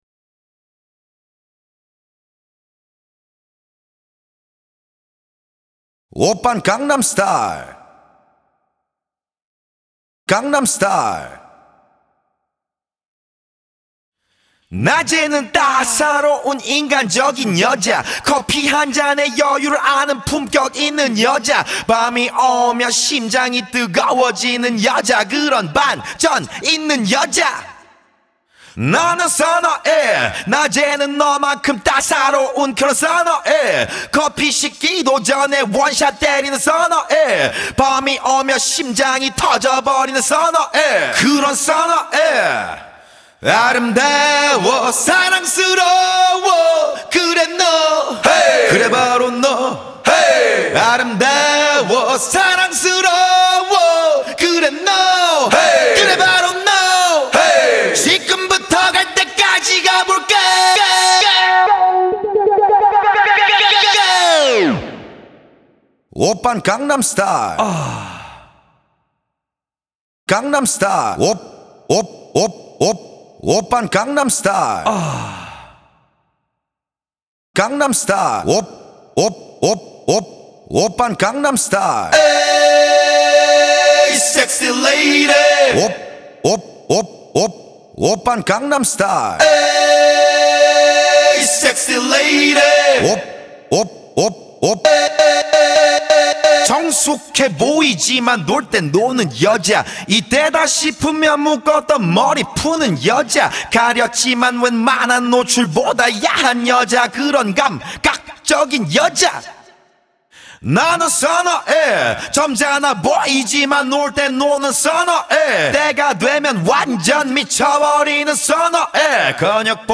Первые секунды тишина (это норм)